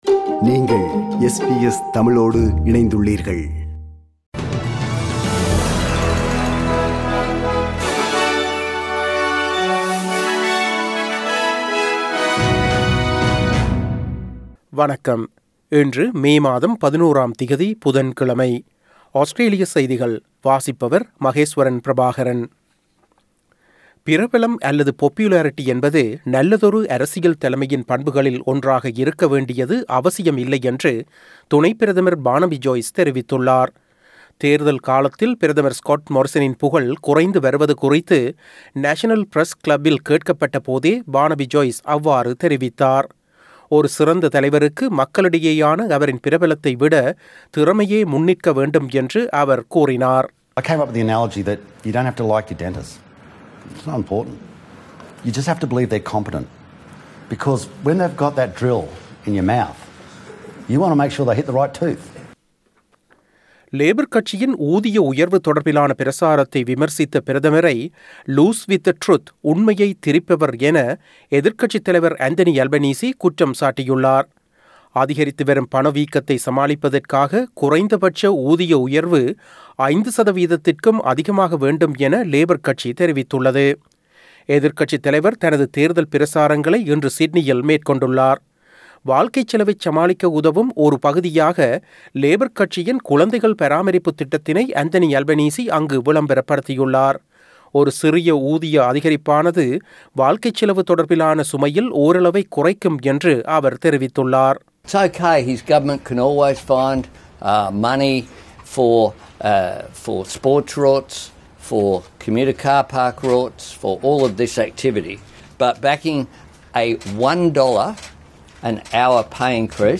Australian news bulletin for Wednesday 11 May 2022.